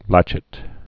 (lăchĭt)